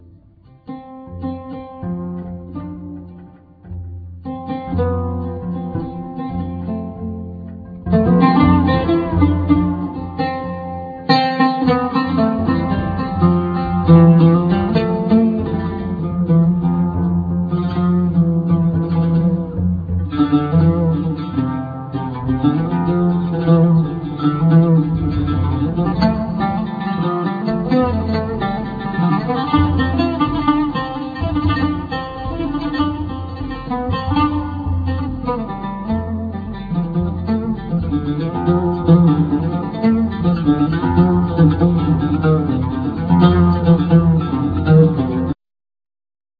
Bass,Piano
Hammond Organ
Electric Bass
Oud
Percussion,Vocals